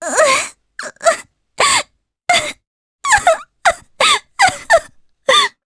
Mediana-Vox_Sad_jp.wav